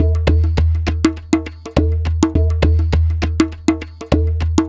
TR PERCS 1.wav